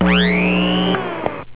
vacuum
vacuum.au